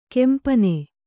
♪ kempane